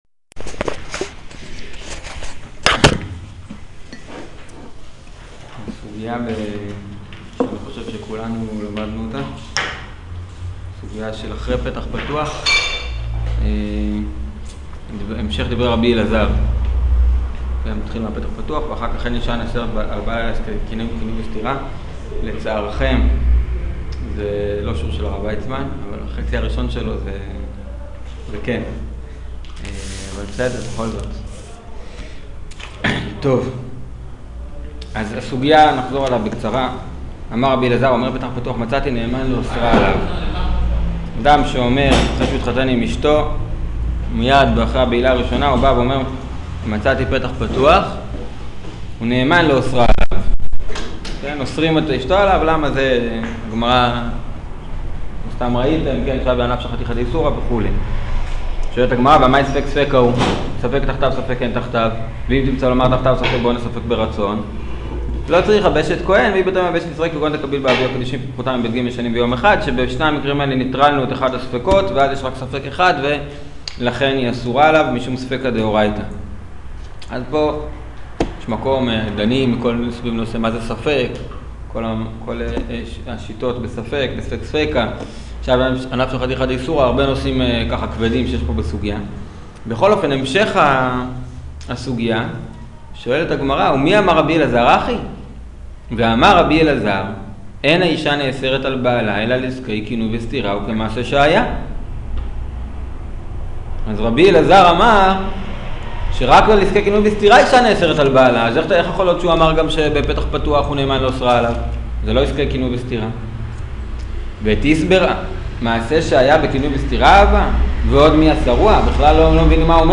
שיעור עסקי קינוי וסתירה